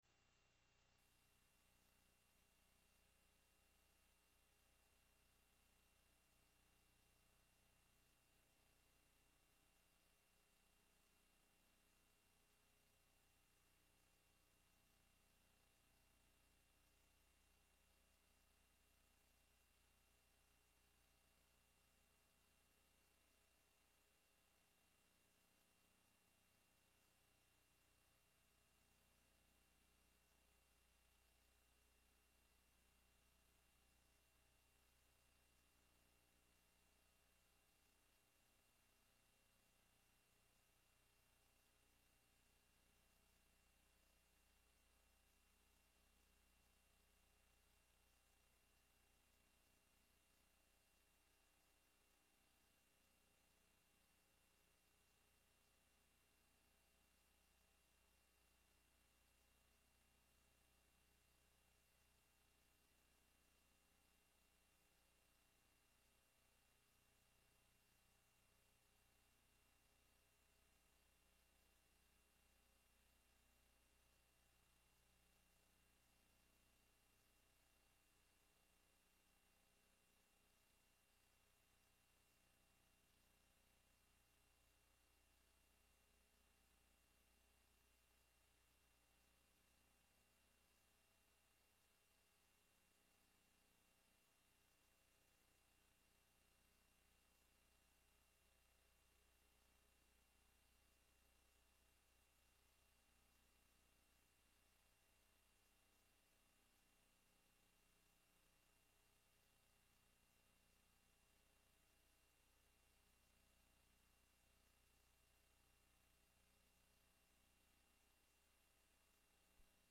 rueda-de-prensa-estudia-en-linea-en-la-udeg-oferta-2025-b.mp3